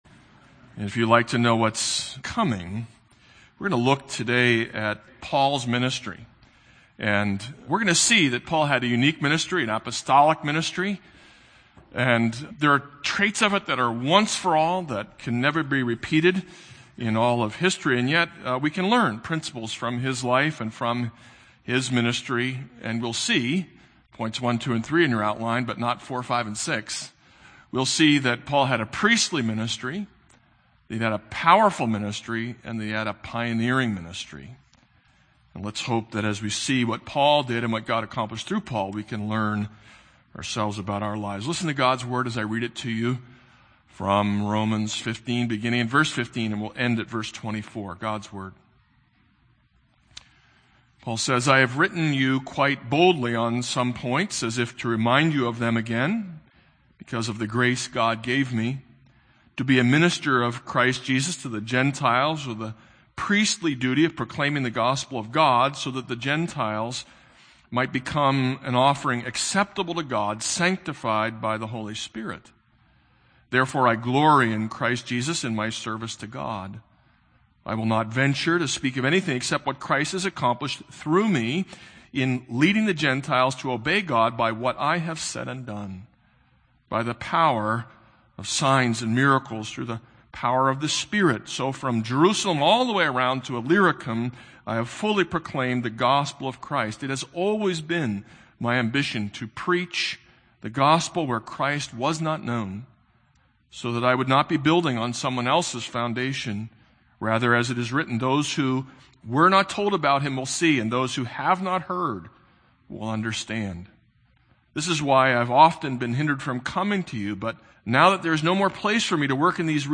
This is a sermon on Romans 15:15-33.